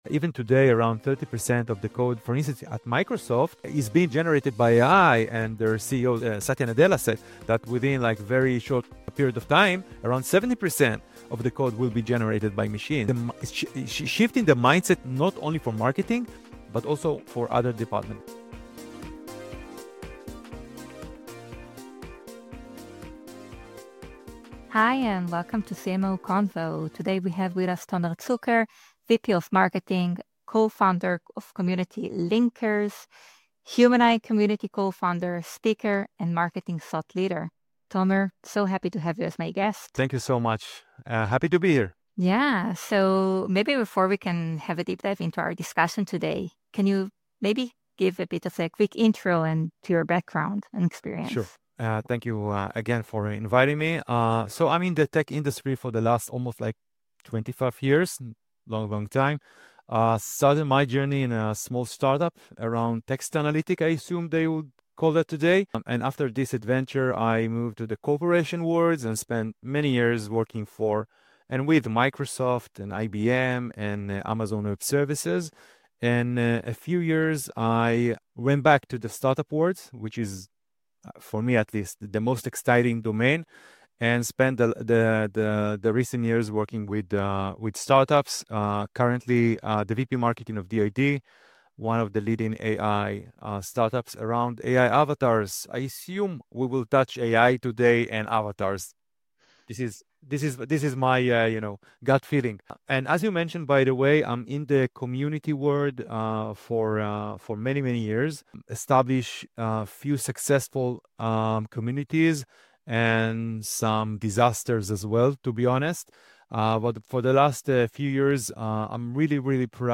In each episode, we have in-depth conversations with CMOs and top-level marketers from around the world, across every industry and level of experience, in order to get their insights into what it takes to excel at the very top of the marketing hierarchy.